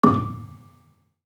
Gamelan Sound Bank
Gambang-D5-f.wav